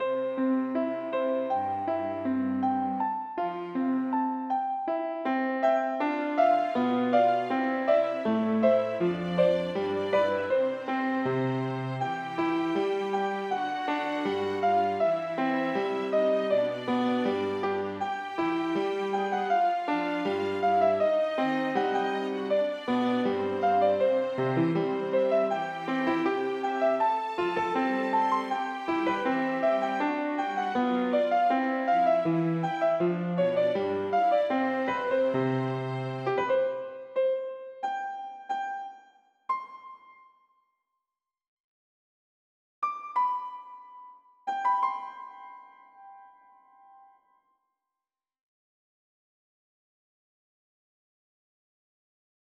Here is the latest version, using the new software, of my arrangement of this artwork’s music.
This artwork is a children’s song with a classical musical twist, so the logical range of colors would be bright multiple pure colors to black and deep browns.